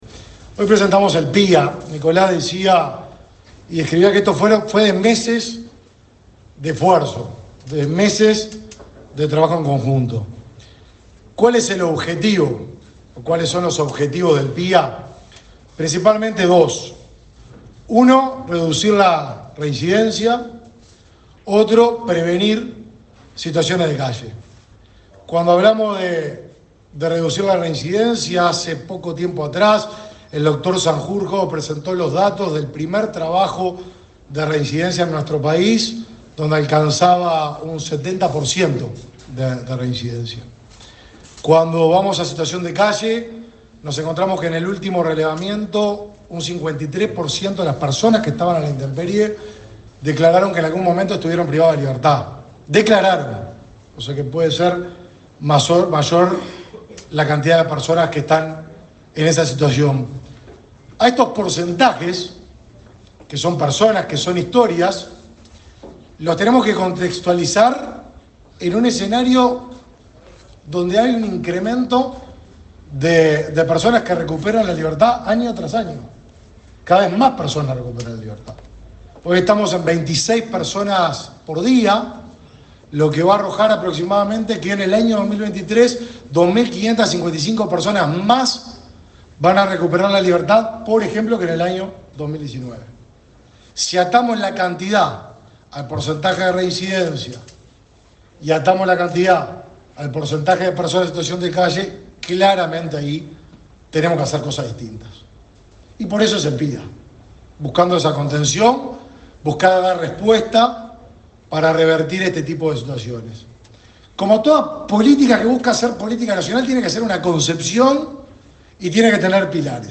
Palabras del ministro de Desarrollo Social, Martín Lema
Palabras del ministro de Desarrollo Social, Martín Lema 30/11/2023 Compartir Facebook X Copiar enlace WhatsApp LinkedIn Este jueves 30, el ministro de Desarrollo Social, Martín Lema, participó de la presentación del Proyecto de Inclusión Asistida (PIA), dirigido a la reinserción social de la población egresada de la Unidad n.°4 del Instituto Nacional de Rehabilitación.